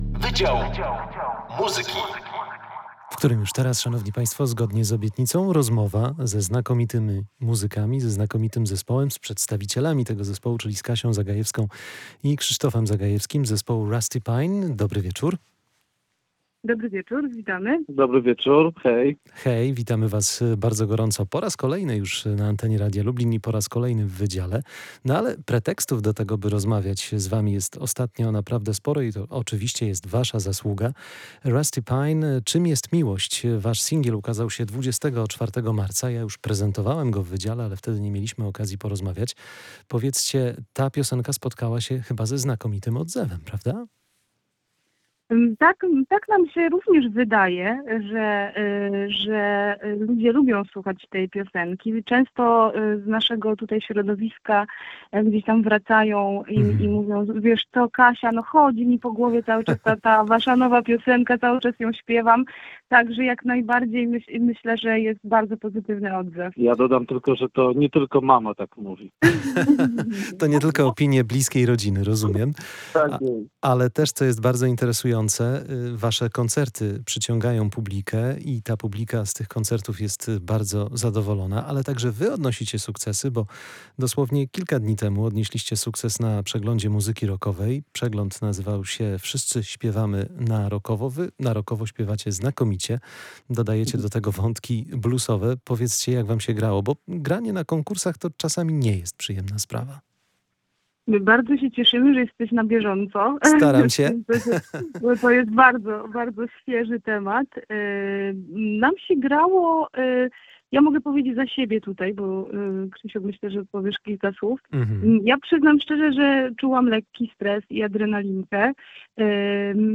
Wydział Muzyki: Zespół Rusty Pine nie zwalnia tempa [POSŁUCHAJ ROZMOWY]